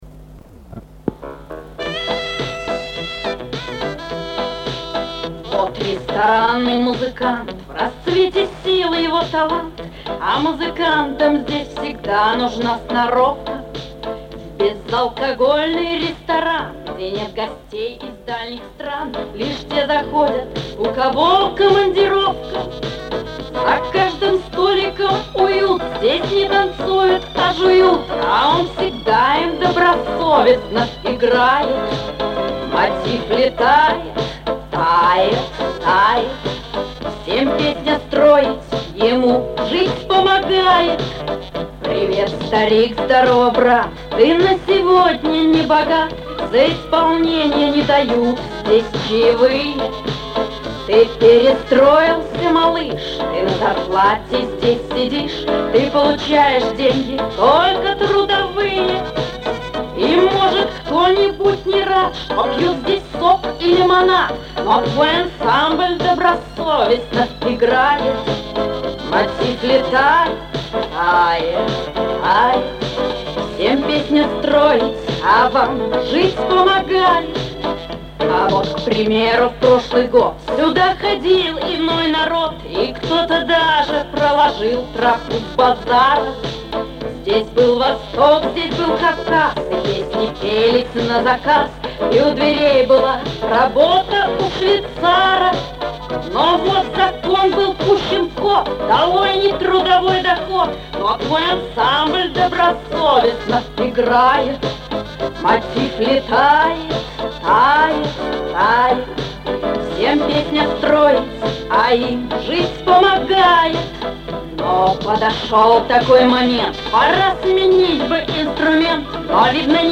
эстрадный шансон